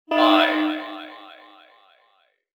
selfdestructfive.wav